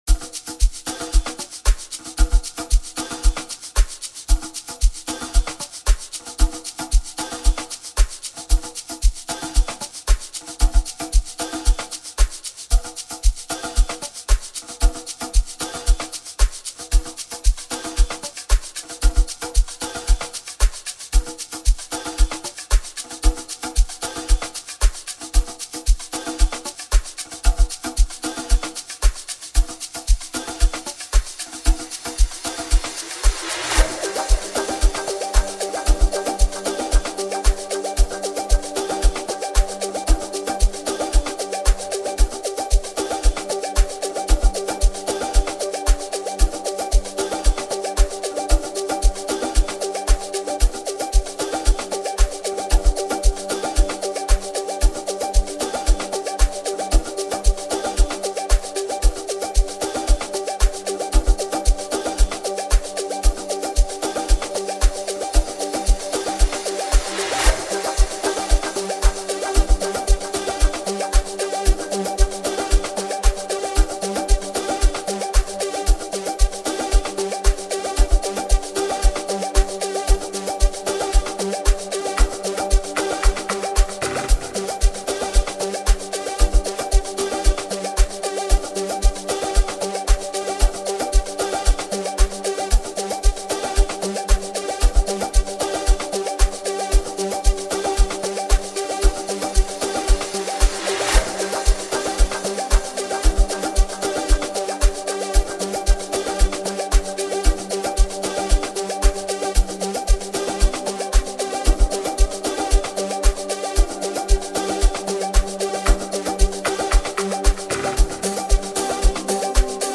Just like you'd expect, the song slaps pretty hard.